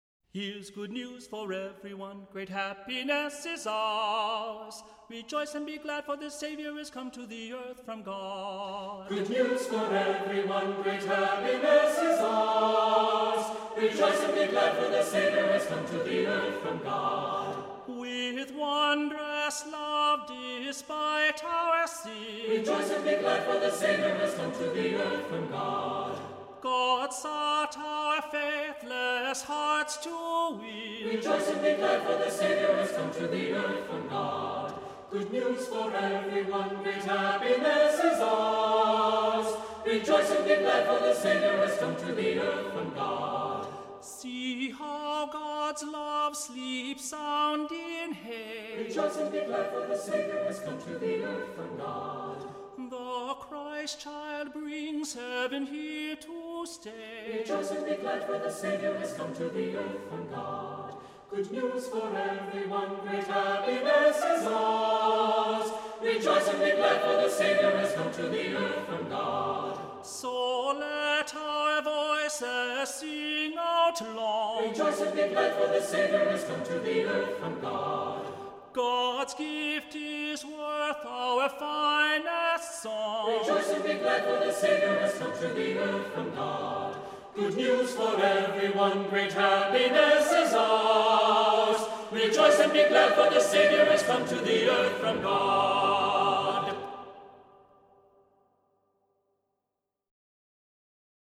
Voicing: SATB; Cantor